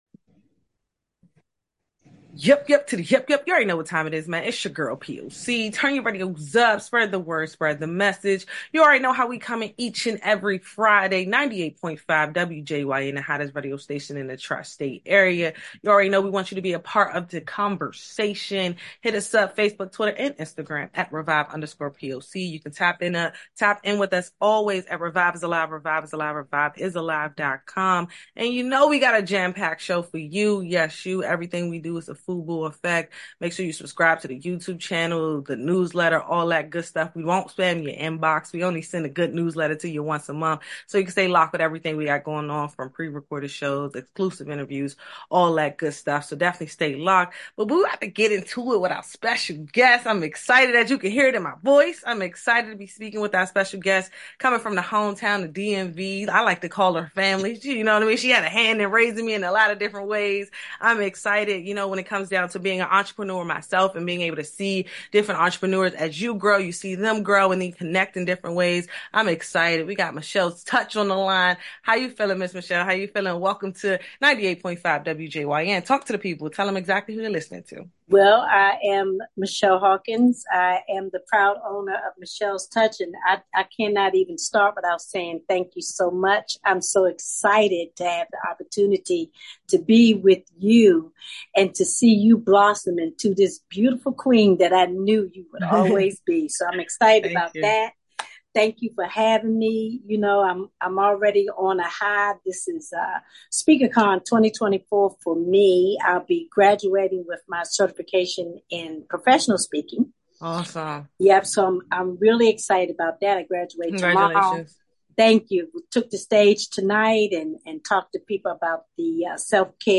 In the interview, she addressed concerns about the rising costs of beauty services, which she attributes to the increasing demand and the skill required to meet that demand.